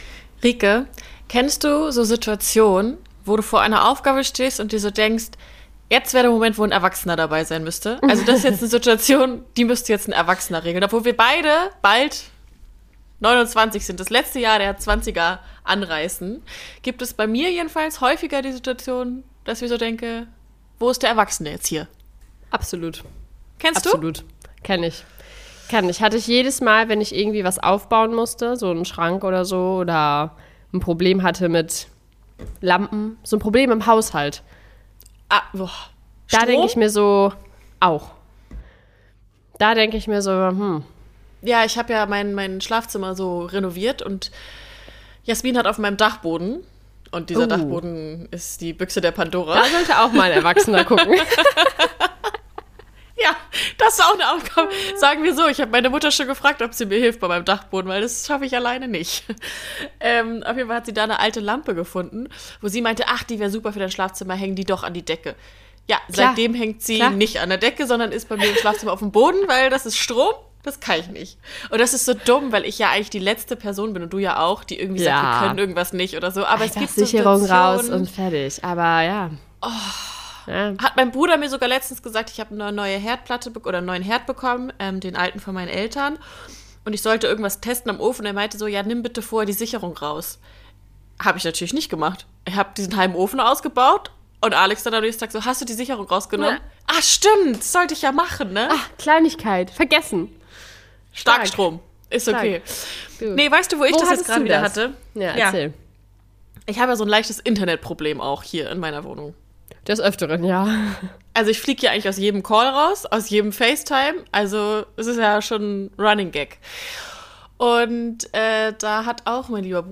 Freut euch ebenfalls auf eine sehr musikalische Folge und einen super Tipp der Woche.